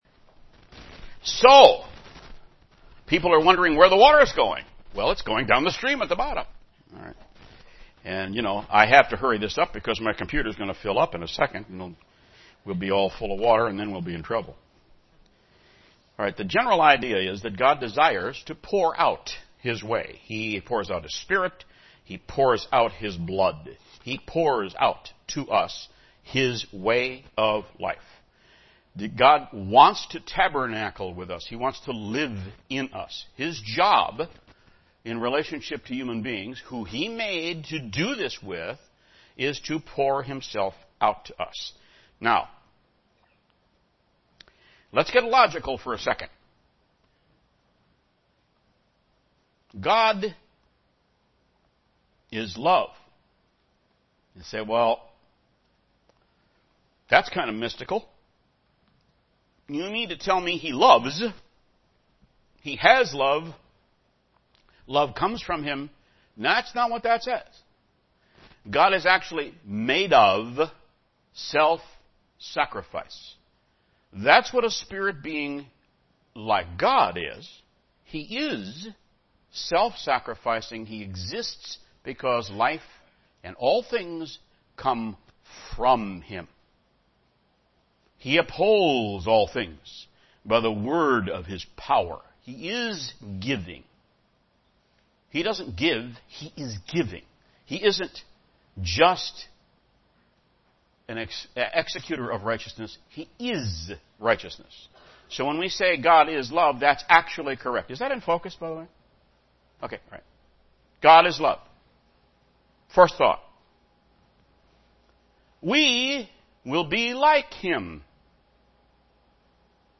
Print Sequence of steps to God's way UCG Sermon Studying the bible?